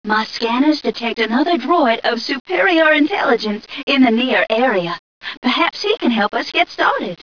mission_voice_m2ca002.wav